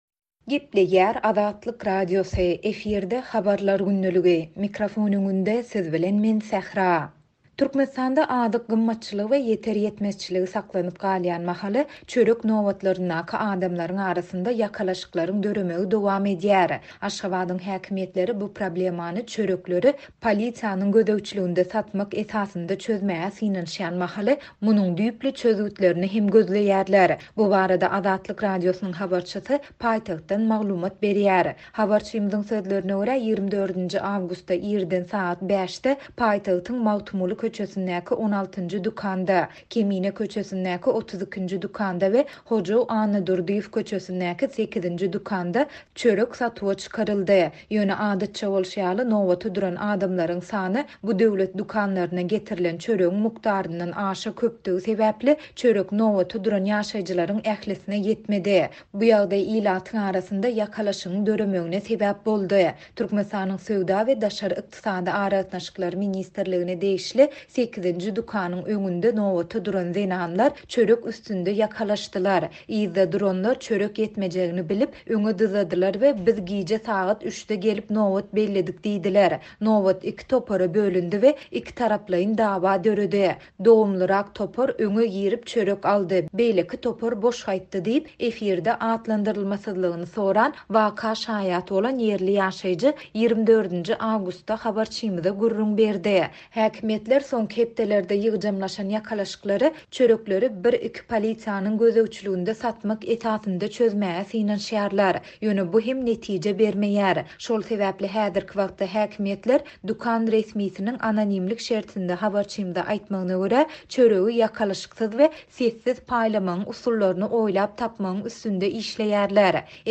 Bu barada Azatlyk Radiosynyň habarçysy paýtagtdan maglumat berýär.